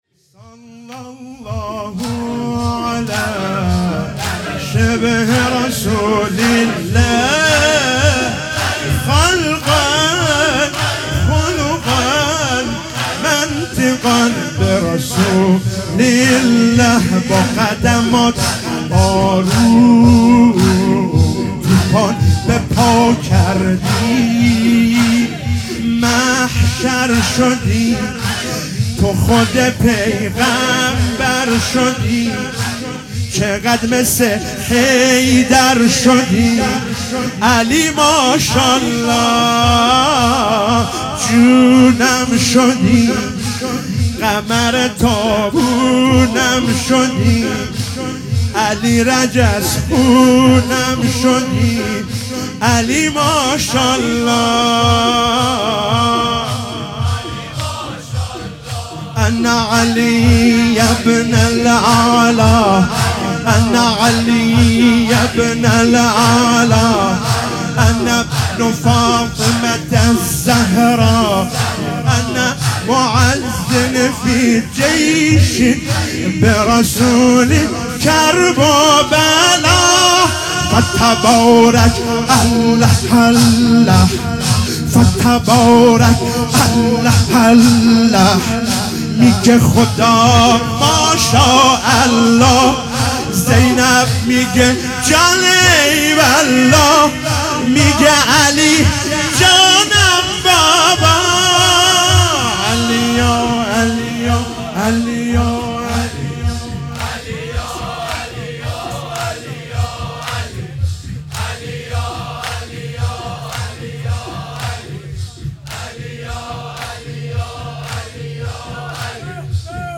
شب هشتم محرم 97 - زمینه - صلی الله علی شبح رسول الله